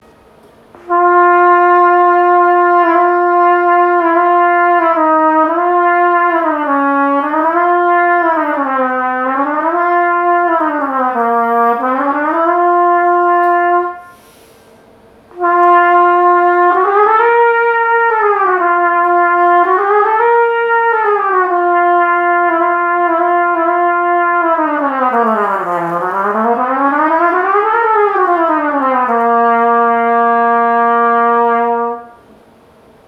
真ん中のF（ソ）からリラックスした息遣いで半音づつ下がっていく、もしくは上がっていく
吹きやすい音域のスケールをスラーで１オクターブ。息の軽く流す感覚で